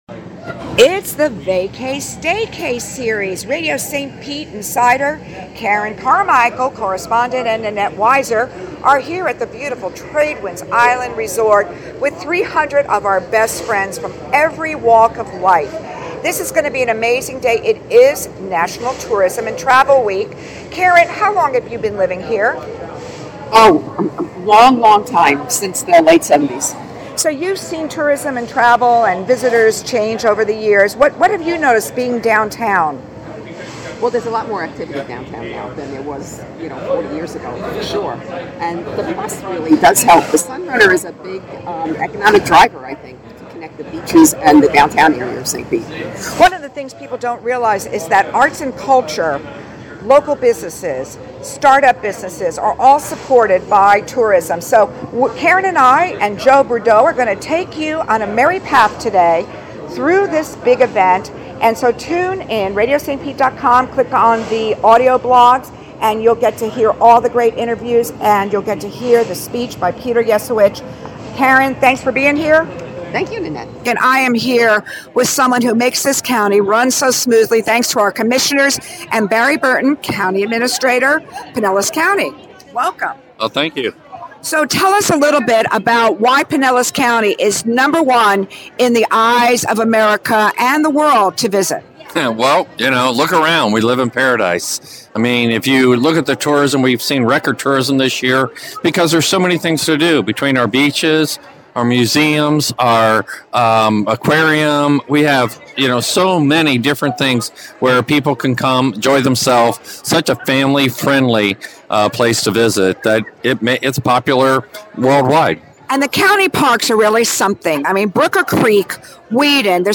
(1) Staycay/Vacay Interviews with local tourism leaders.